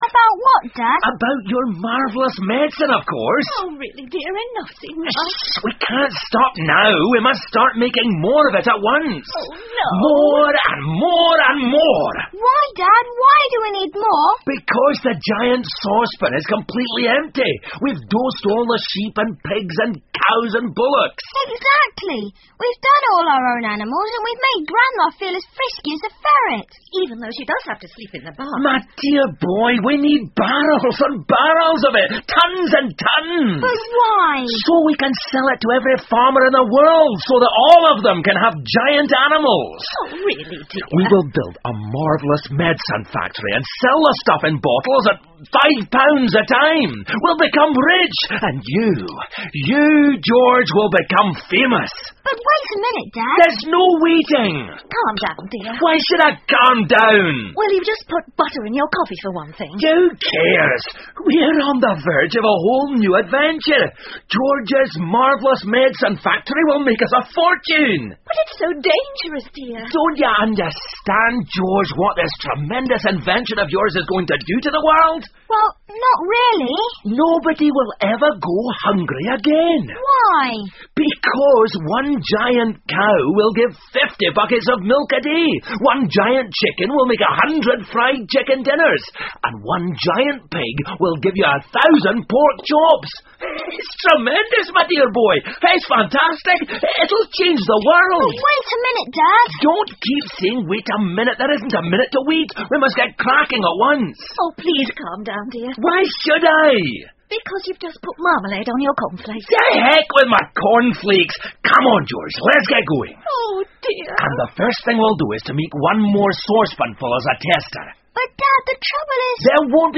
乔治的神奇药水 George's Marvellous Medicine 儿童广播剧 11 听力文件下载—在线英语听力室